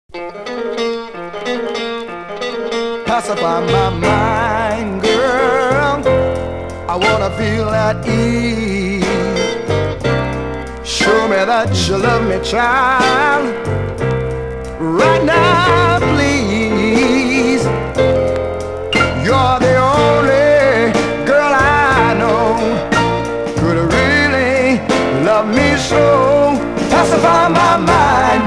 (税込￥3080)   UK SOUL